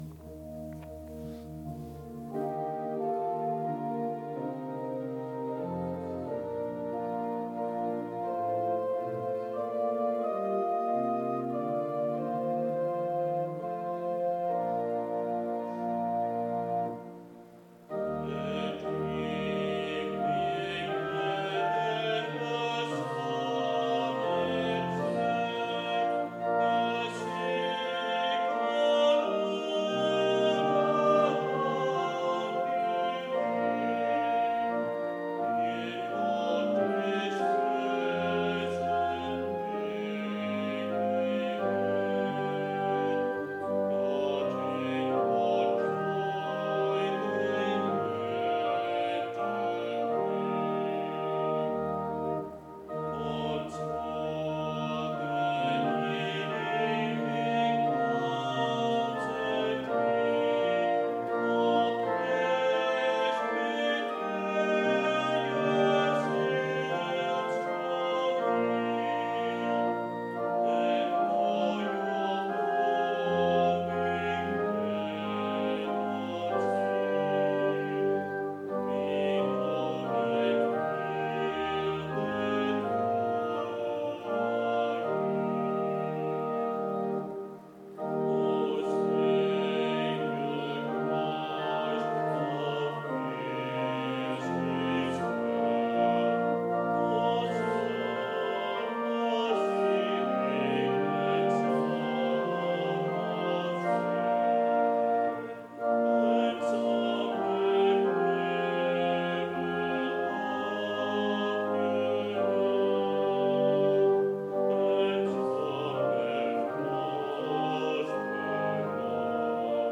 Hymn, readings and homily for Evensong.